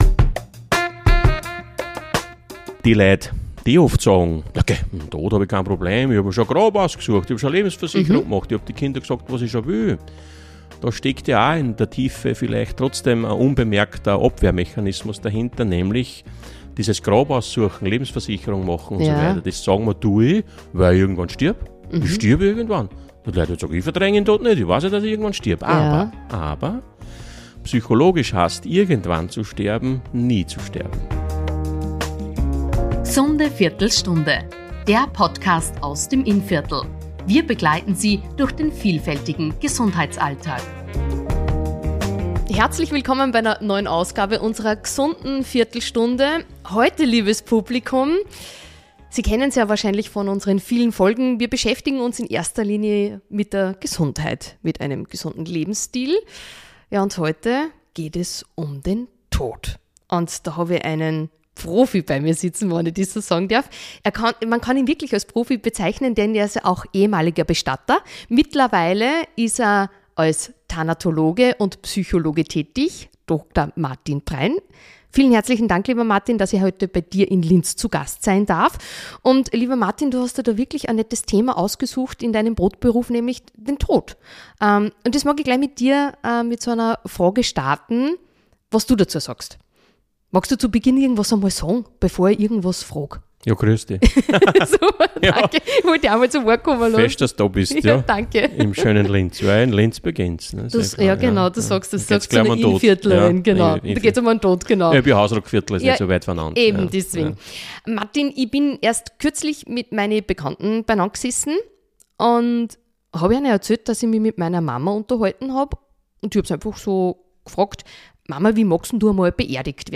Warum verdrängen wir unsere Endlichkeit? Wie beeinflusst das unser Leben – und unsere Angst? Ein tiefgründiges Gespräch über Sterben, Spiritualität, Mitgefühl und das, was bleibt.